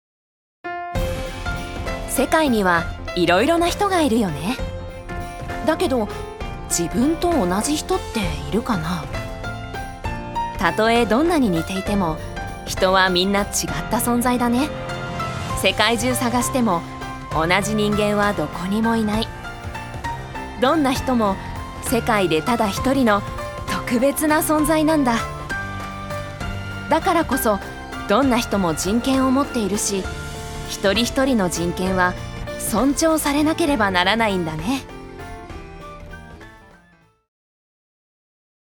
女性タレント
ナレーション４